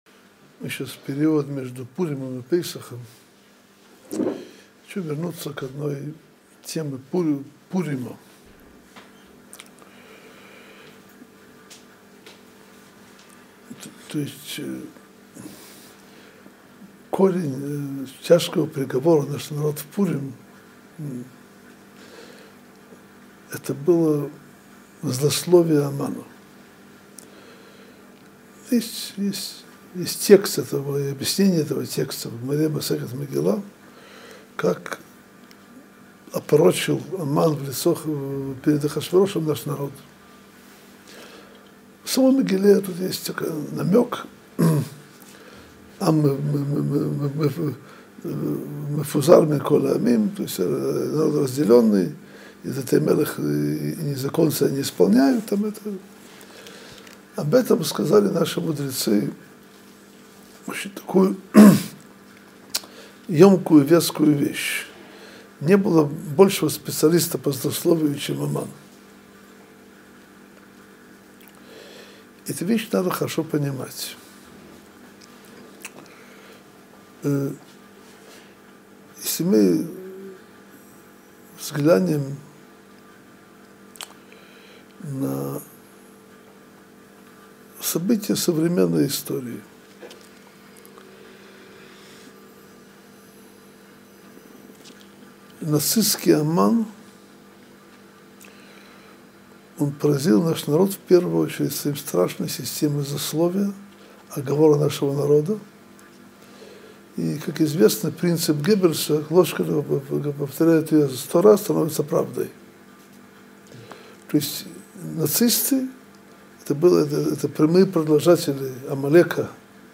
Содержание урока: Период между Пуримом и Песахом.